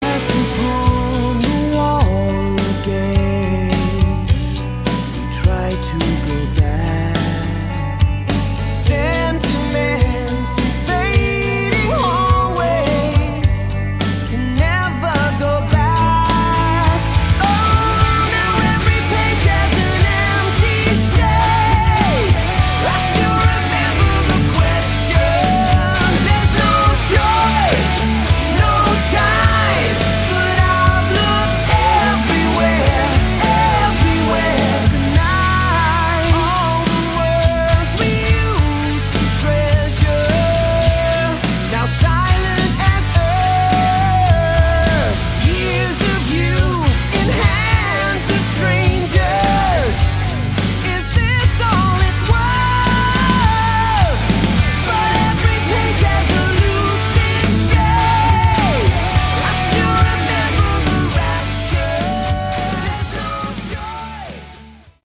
Category: Hard Rock
guitar
vocals
bass
drums